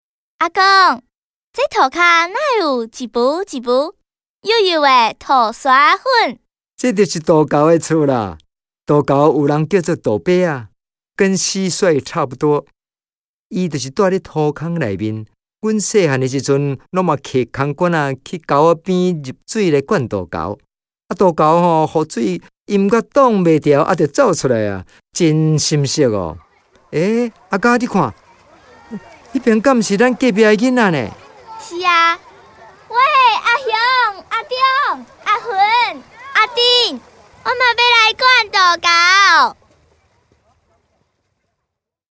(旁白)